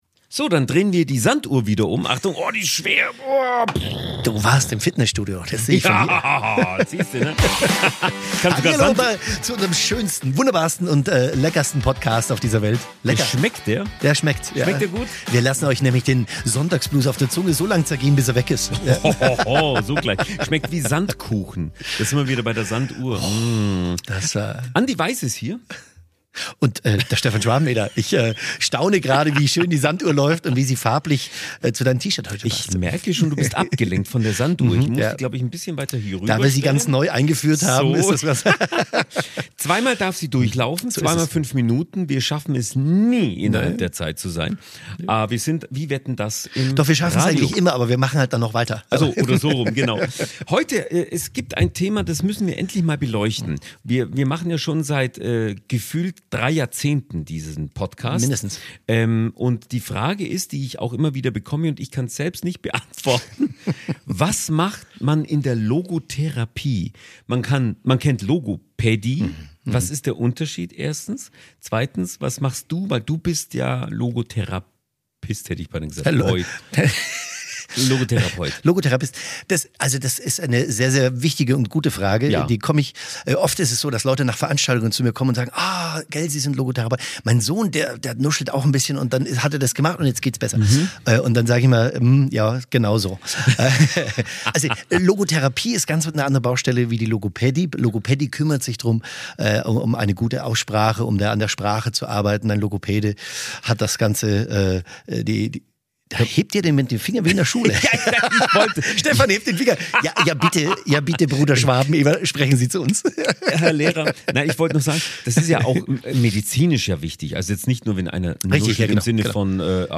Ein inspirierendes Gespräch über Sinn, Hoffnung und die Kraft, trotz allem Ja zum Leben zu sagen.